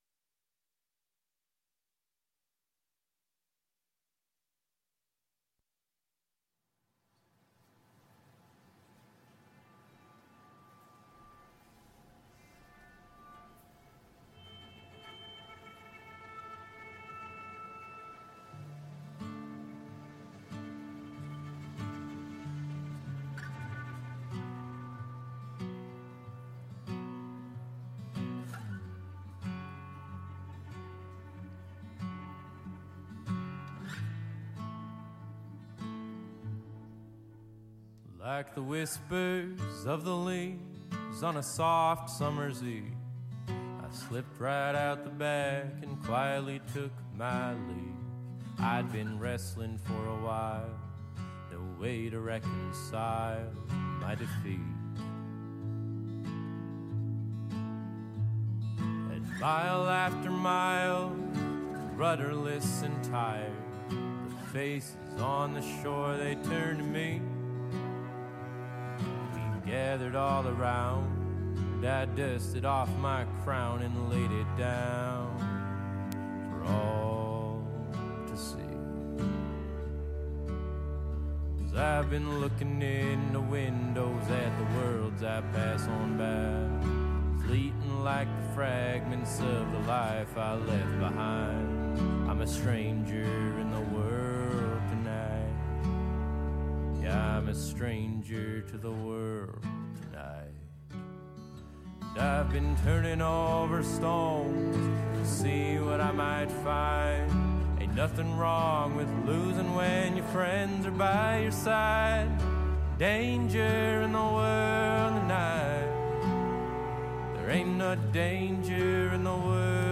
Right here on KDRT 95.7FM in Davis, California. Listening Lyrics is a genre free zone - we feature the artist.